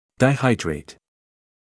[daɪ’haɪdreɪt][дай’хайдрэйт]дигидрат